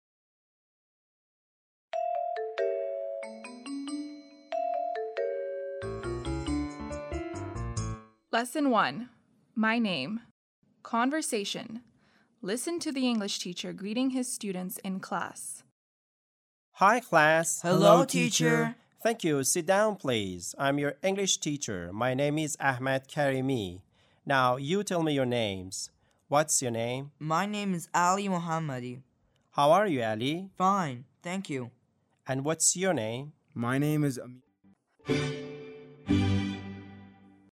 7-Lesson1-Conversation